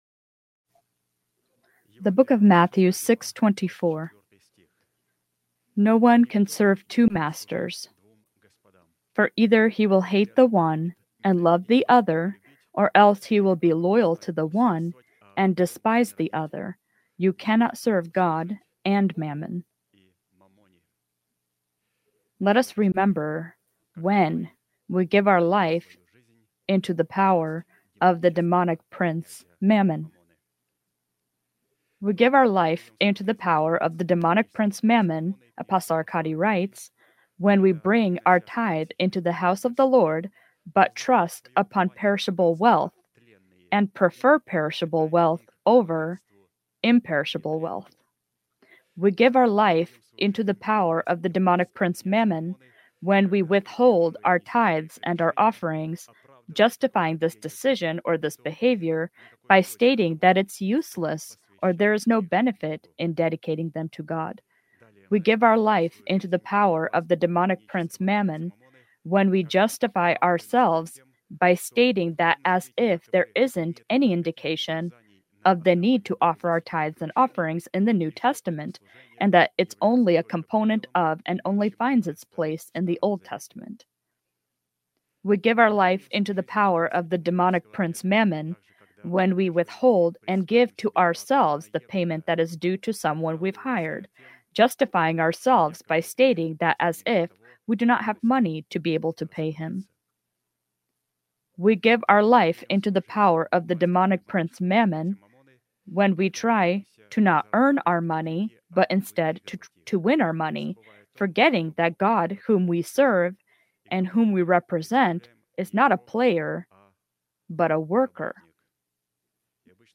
Sermon title: